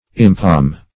Impalm \Im*palm"\, v. t. To grasp with or hold in the hand.